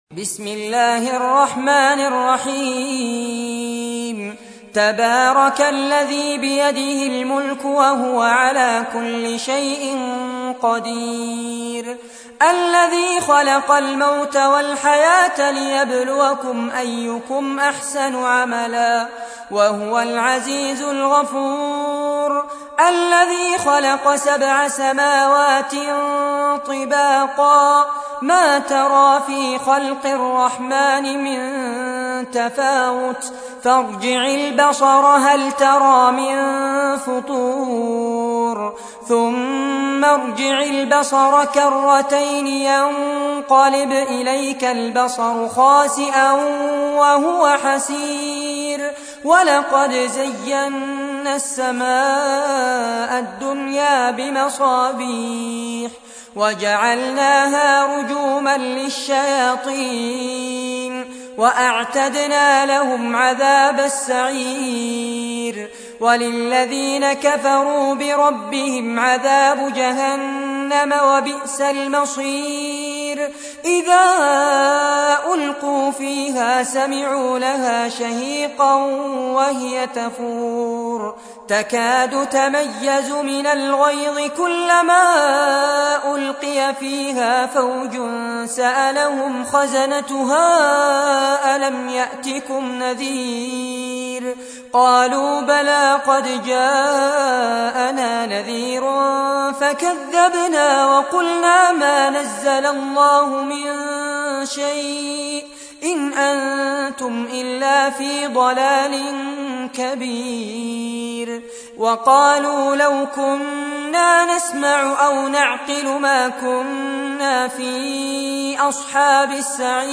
تحميل : 67. سورة الملك / القارئ فارس عباد / القرآن الكريم / موقع يا حسين